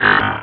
Cri de Medhyèna dans Pokémon Rubis et Saphir.